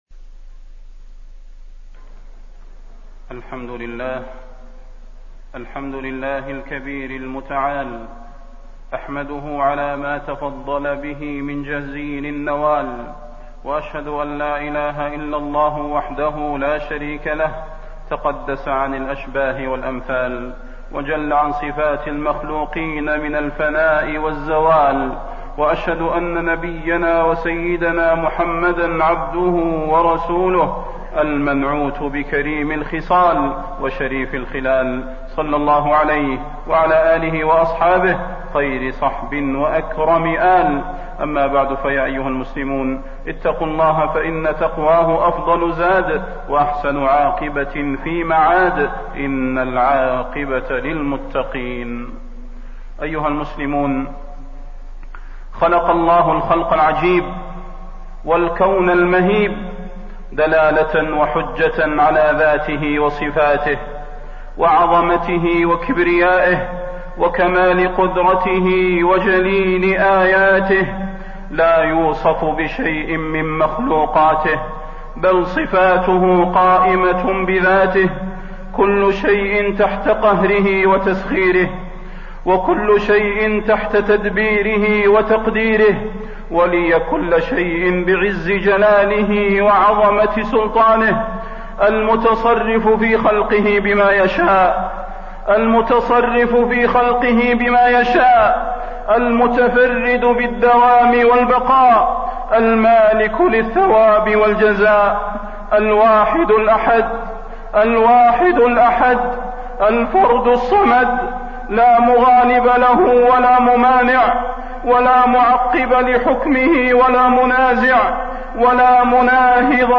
تاريخ النشر ٢٢ محرم ١٤٣١ هـ المكان: المسجد النبوي الشيخ: فضيلة الشيخ د. صلاح بن محمد البدير فضيلة الشيخ د. صلاح بن محمد البدير وجوب طاعة الله وعبادته دون سواه